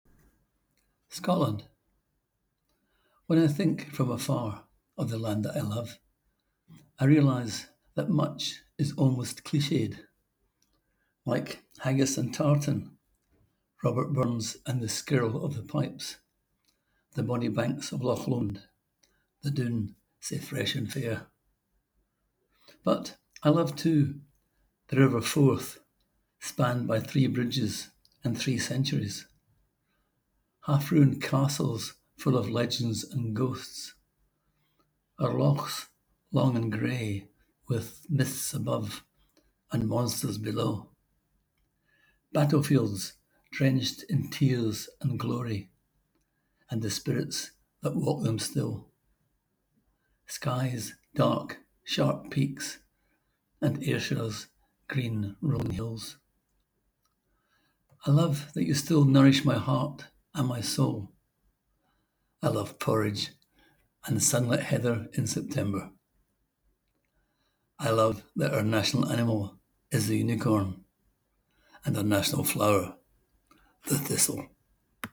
Click here to hear the poet read his words: